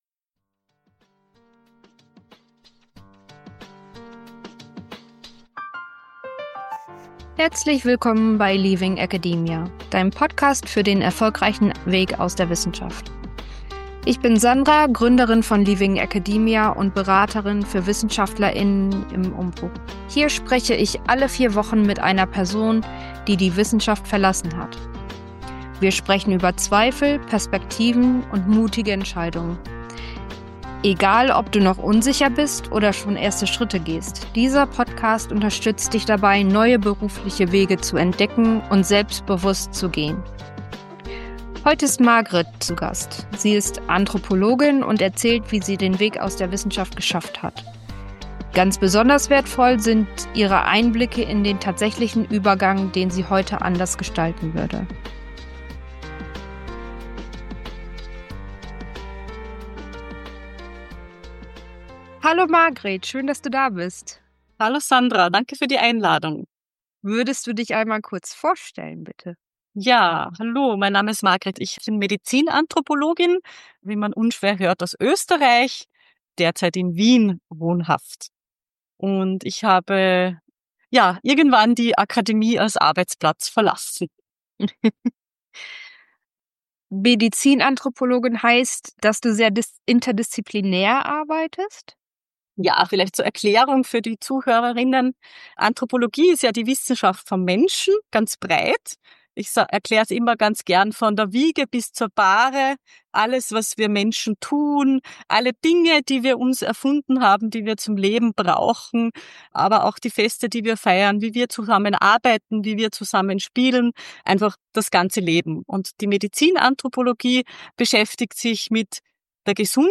Deshalb war es für mich auch eine wunderbare Gelegenheit, wieder einmal ein längeres Gespräch zu führen und unserem Gespräch merkt man glaub ich auch an, dass wir miteinander vertraut sind.